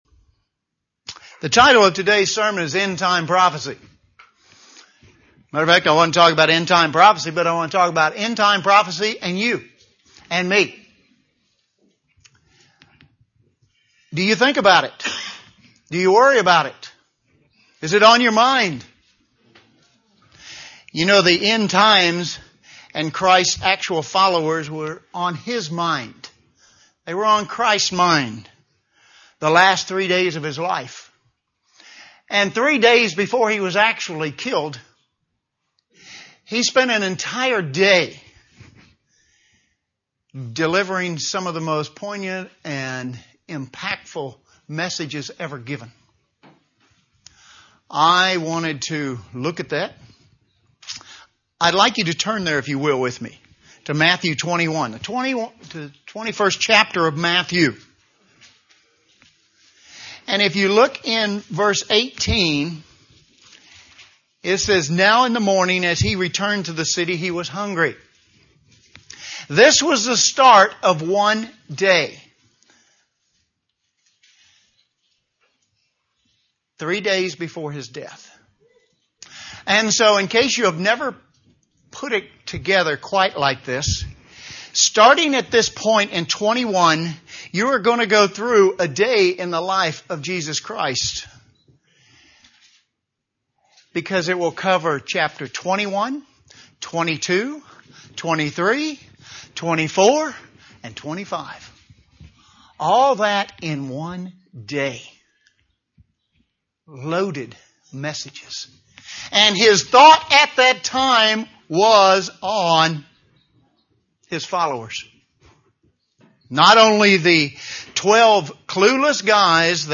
The events predicted by Jesus in Matthew 24 - background on the Olivet Prophecy UCG Sermon Transcript This transcript was generated by AI and may contain errors.